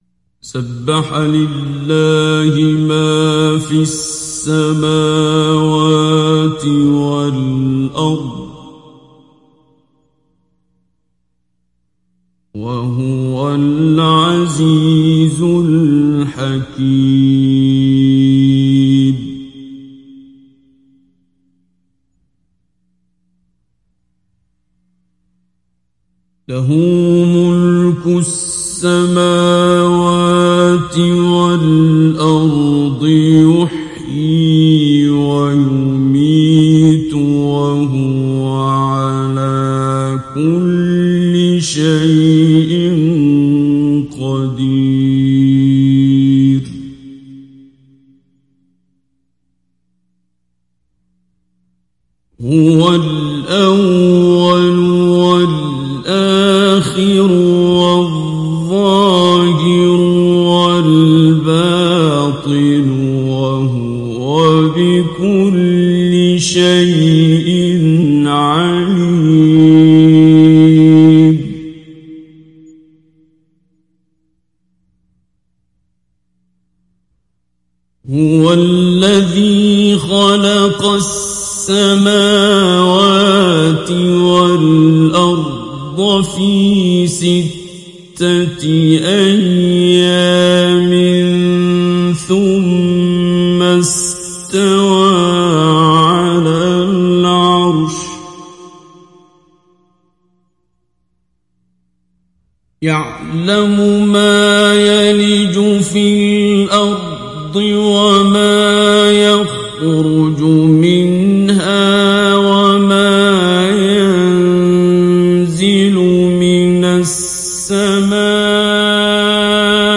تحميل سورة الحديد mp3 بصوت عبد الباسط عبد الصمد مجود برواية حفص عن عاصم, تحميل استماع القرآن الكريم على الجوال mp3 كاملا بروابط مباشرة وسريعة
تحميل سورة الحديد عبد الباسط عبد الصمد مجود